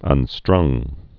(ŭn-strŭng)